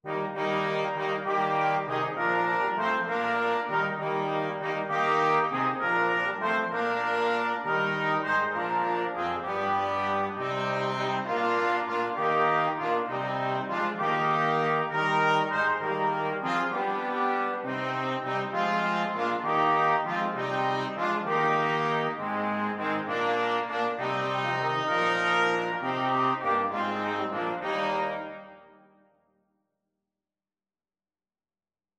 Trumpet 1Trumpet 2French HornTrombone
Eb major (Sounding Pitch) (View more Eb major Music for Brass Quartet )
3/4 (View more 3/4 Music)
Brass Quartet  (View more Easy Brass Quartet Music)
Traditional (View more Traditional Brass Quartet Music)